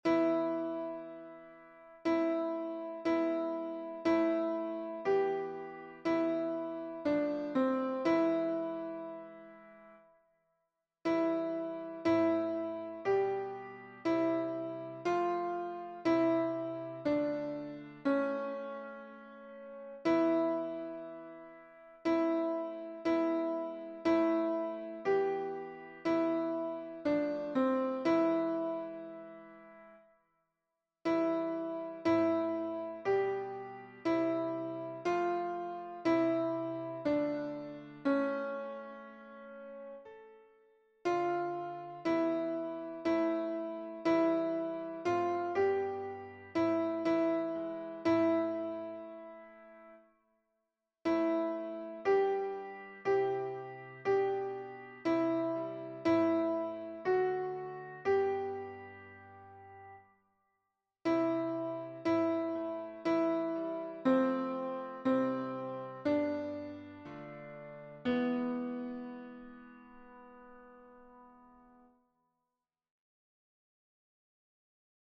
Alt